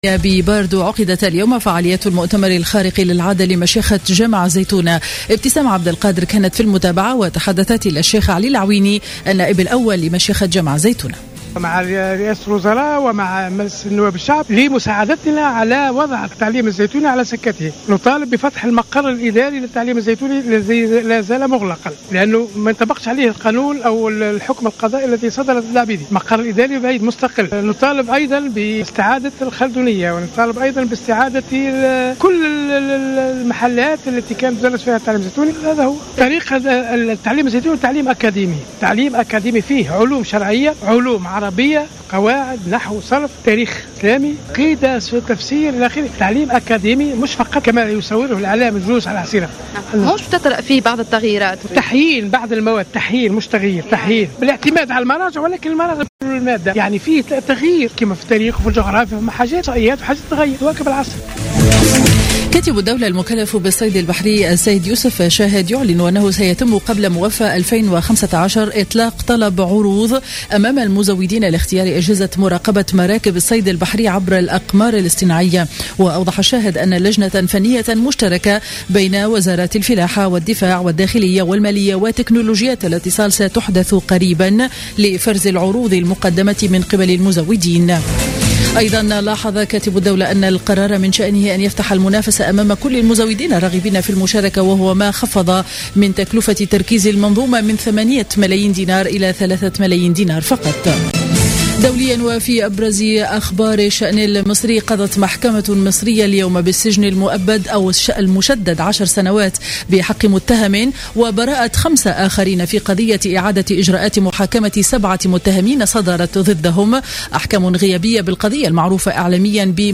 نشرة أخبار منتصف النهار ليوم الأحد 23 أوت 2015